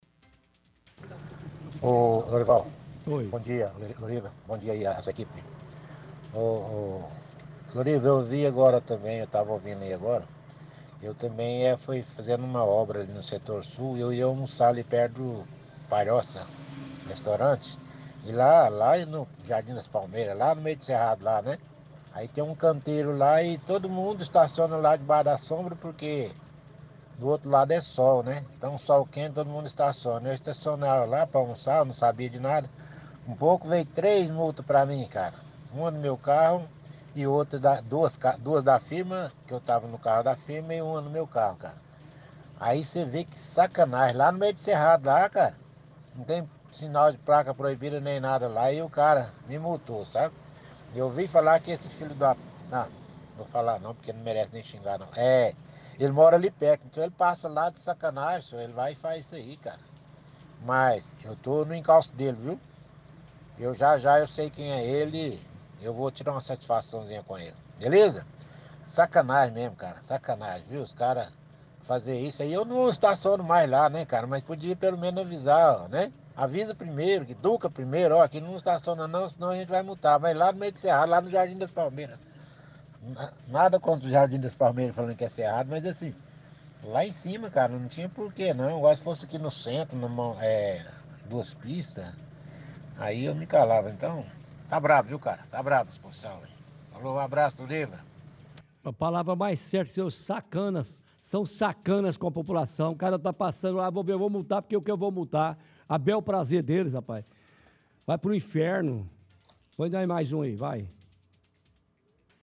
– Ouvinte reclama de multas que levou que são todas aplicadas pelo mesmo agente da Settran.
– Outro ouvinte reclama, que parou em local proibido, e recebeu 3 multas, diz que o agente faz “de sacanagem” sem nem mesmo avisar que vai multar antes.